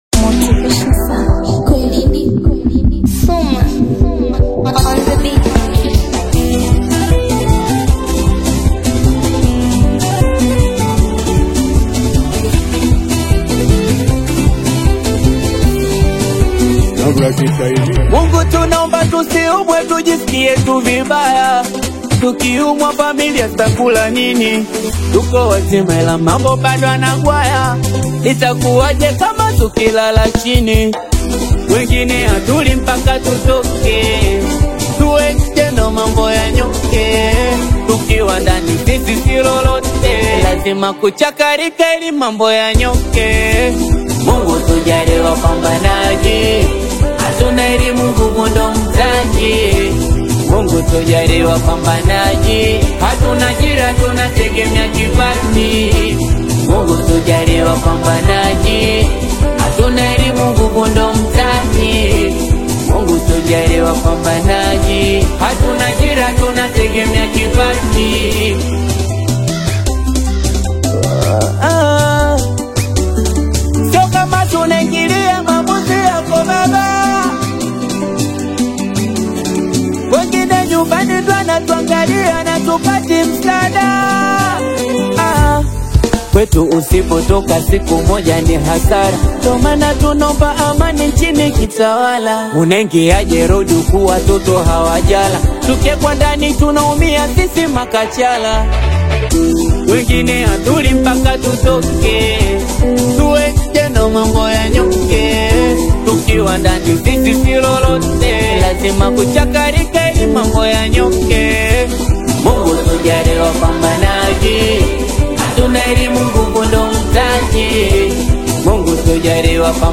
motivational Singeli/Bongo Flava single
energetic street-inspired style